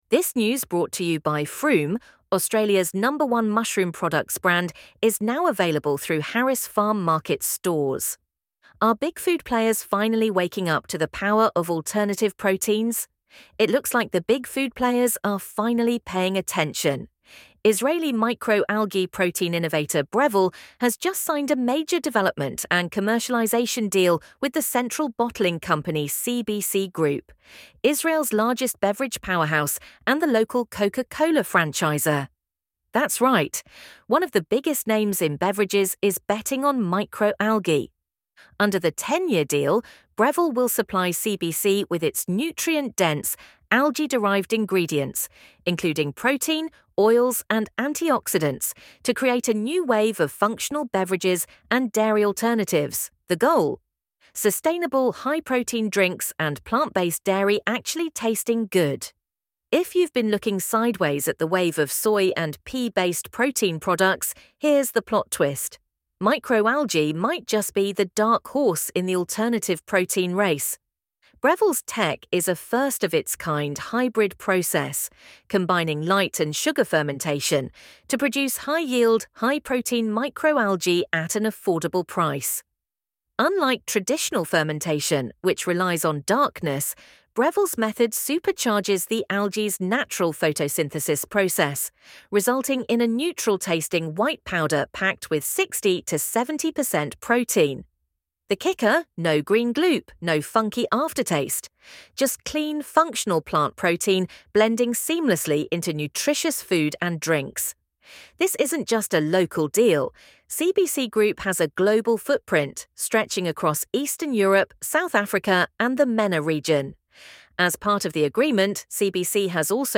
LISTEN TO THIS AUDIO ARTICLE It looks like the big food players are finally paying attention.